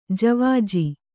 ♪ javāji